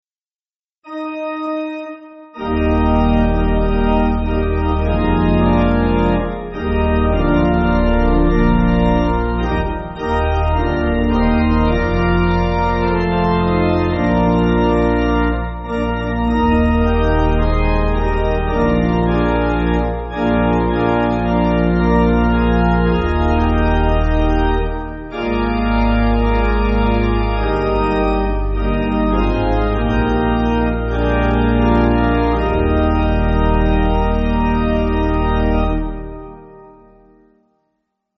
Organ
(CM)   1/Eb